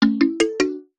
finish_ok.wav